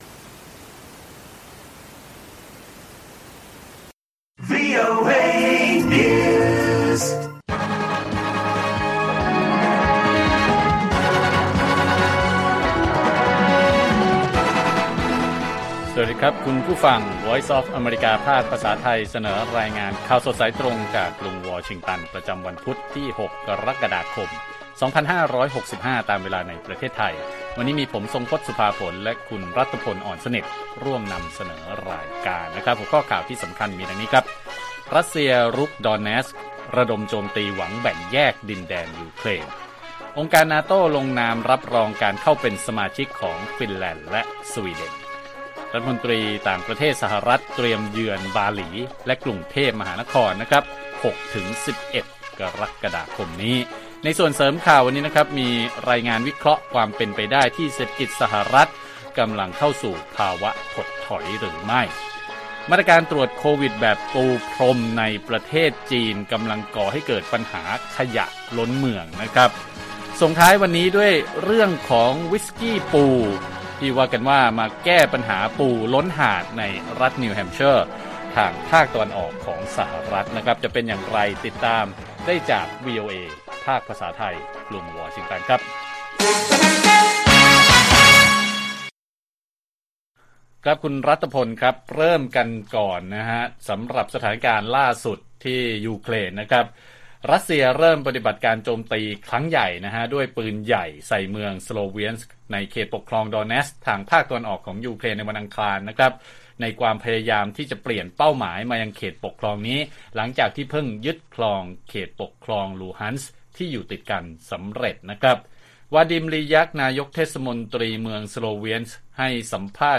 ข่าวสดสายตรงจากวีโอเอไทย 6:30 – 7:00 น. วันที่ 6 ก.ค. 65